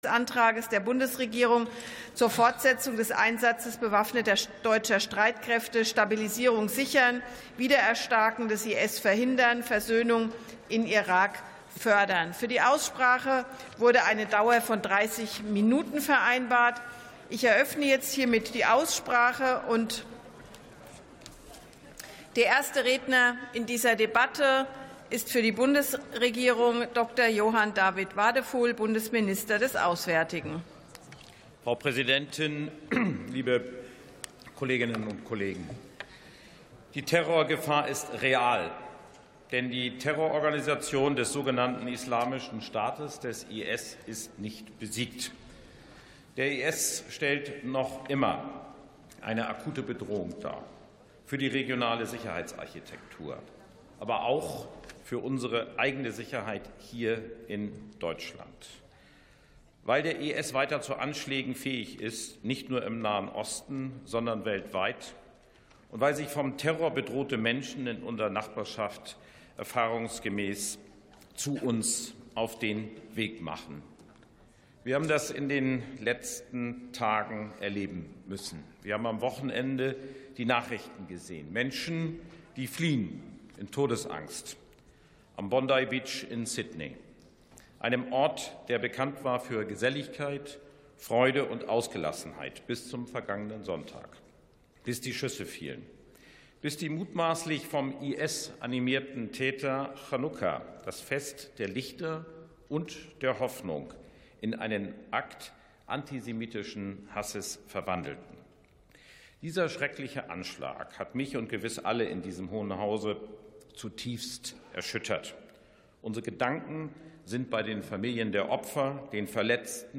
49. Sitzung vom 17.12.2025. TOP 5: Bundeswehreinsatz in Irak ~ Plenarsitzungen - Audio Podcasts Podcast